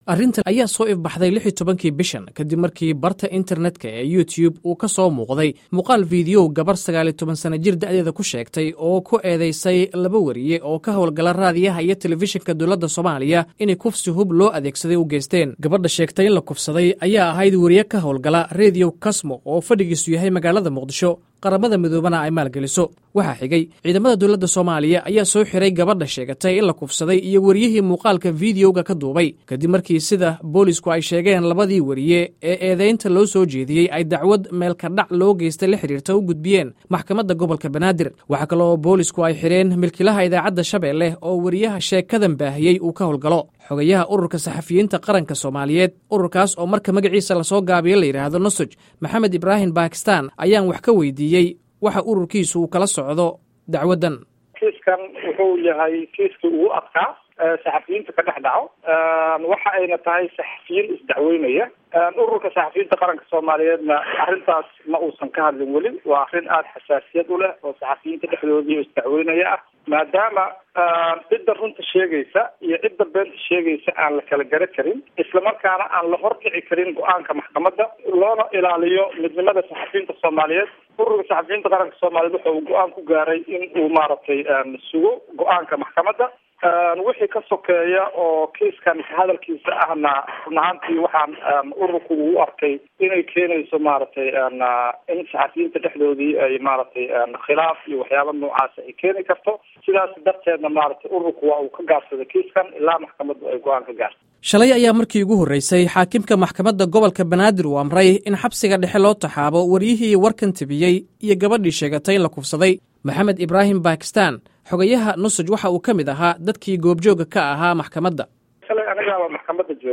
Warbixin: Tuhunka Kufsi ka Dhacay Muqdisho